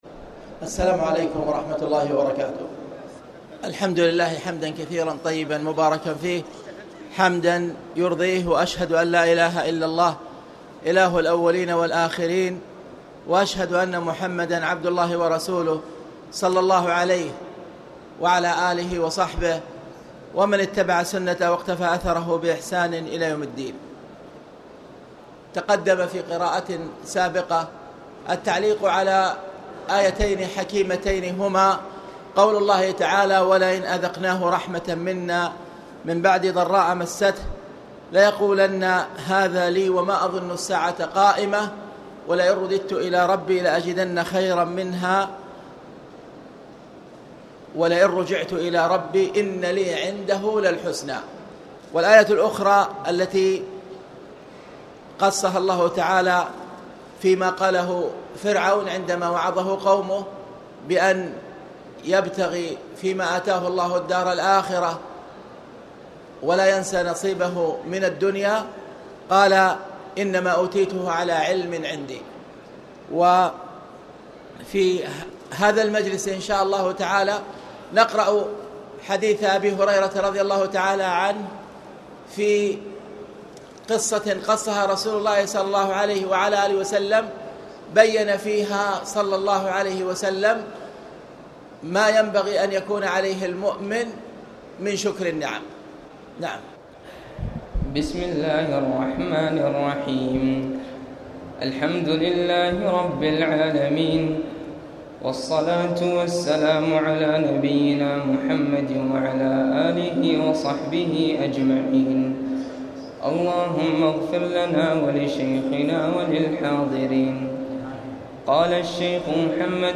تاريخ النشر ١٨ رمضان ١٤٣٨ هـ المكان: المسجد الحرام الشيخ